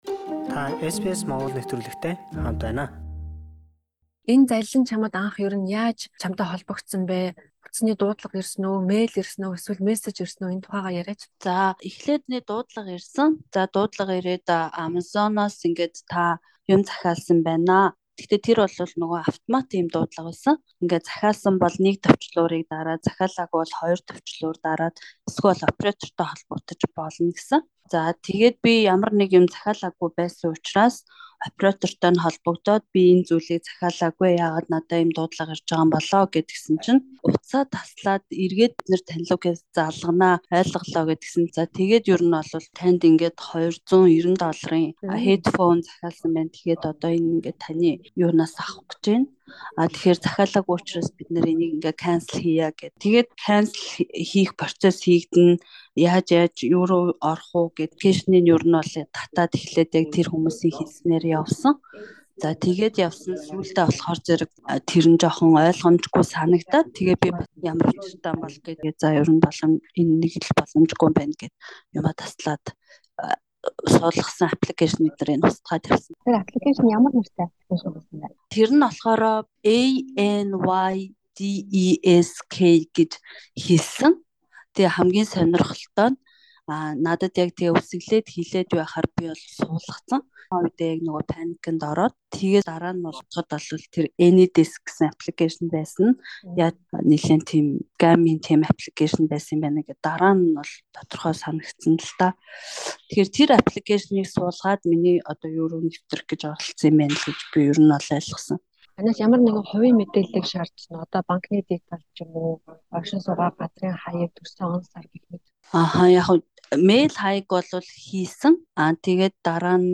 Азаар мөнгө алдаагүй ч бусаддаа сургамж болгох үүднээс бидэнд ярилцлага өгсөн юм.